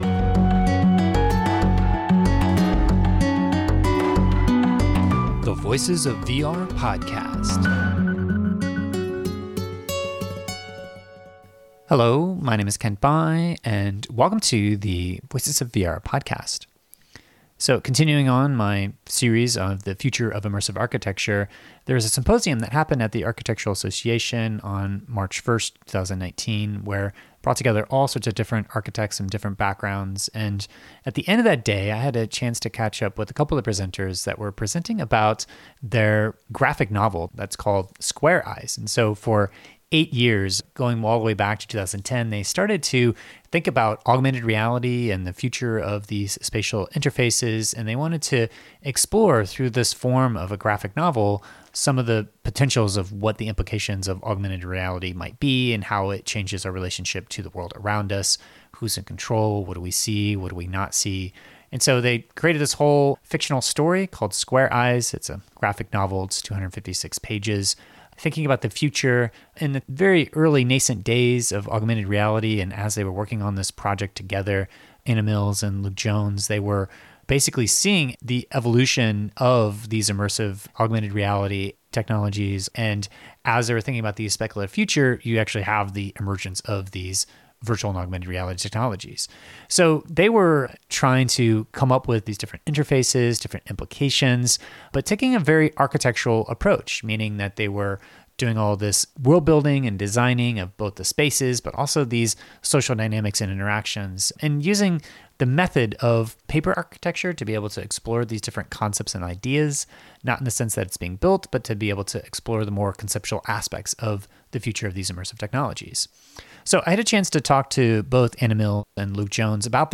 at the end of the Symposium on the Architecture for the Immersive Internet